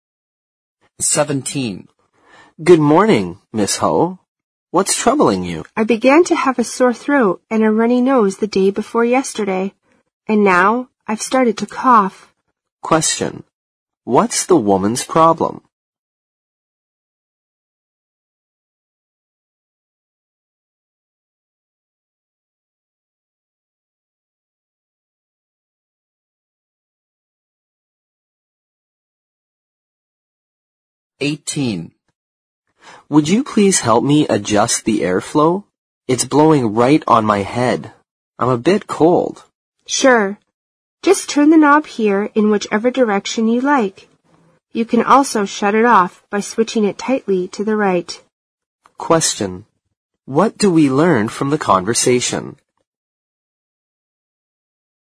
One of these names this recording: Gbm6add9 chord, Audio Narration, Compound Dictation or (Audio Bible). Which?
Compound Dictation